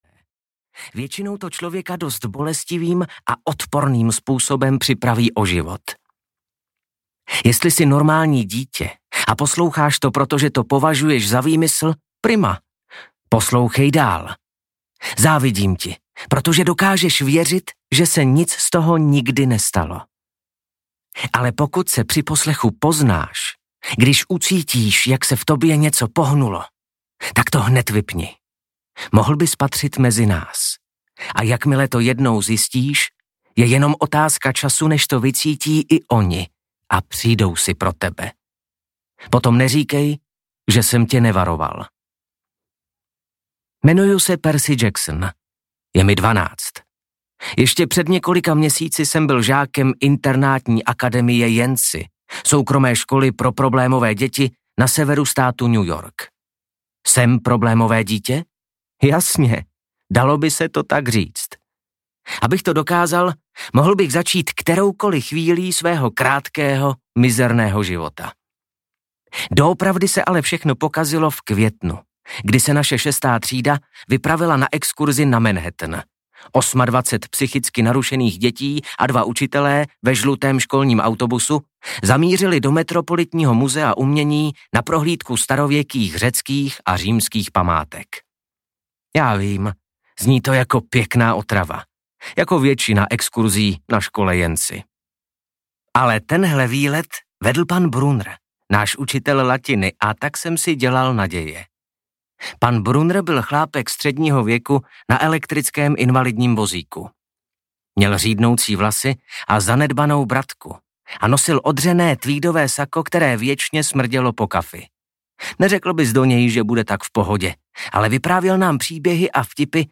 Percy Jackson - Zloděj blesku audiokniha
Ukázka z knihy